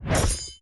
094-Attack06.opus